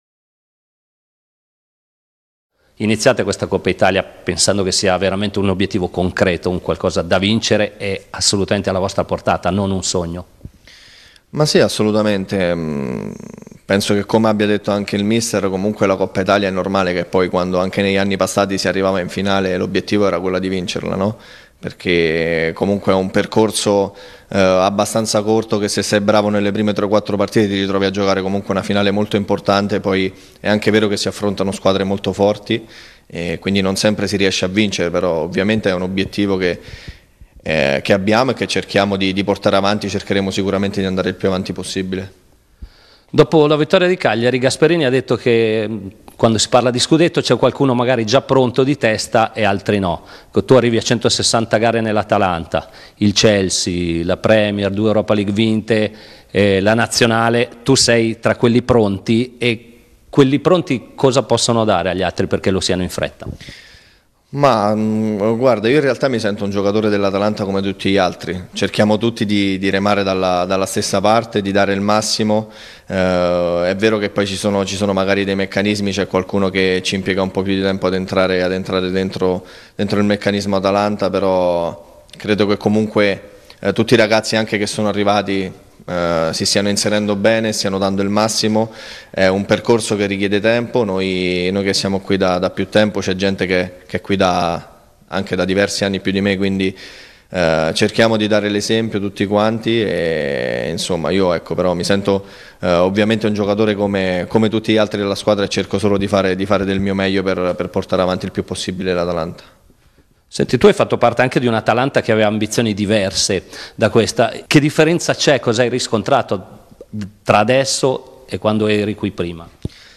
Di Zappacosta riproduciamo audio dell'intervista sempre di Mediaset di circa 4 minuti, parole molto belle, consigliamo l'ascolto